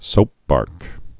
(sōpbärk)